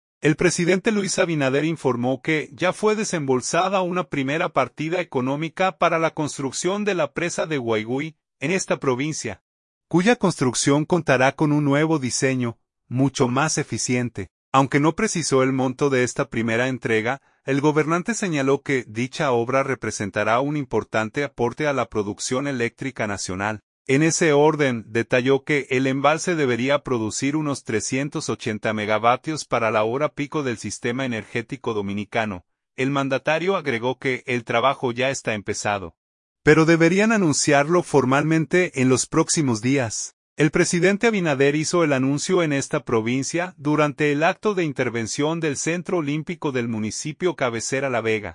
El presidente Abinader hizo el anuncio en esta provincia durante el acto de intervención del Centro Olímpico del municipio cabecera La Vega.